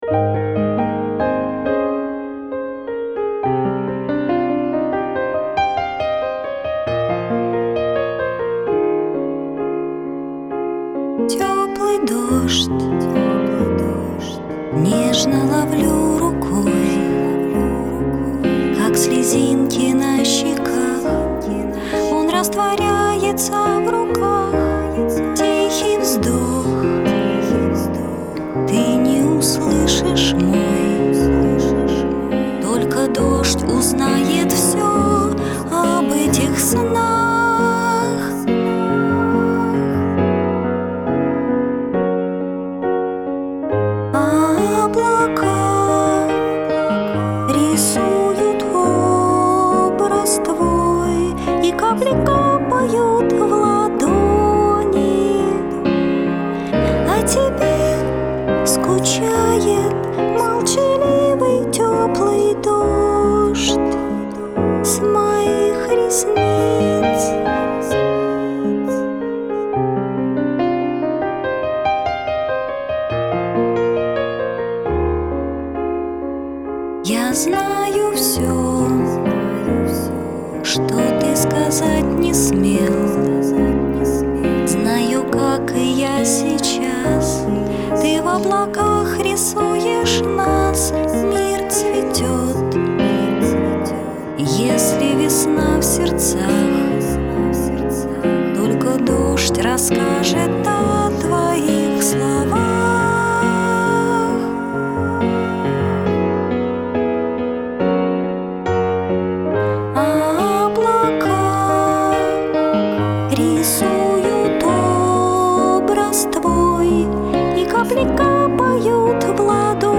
красиво аранжировал
И поэтому записали вспомогательный трек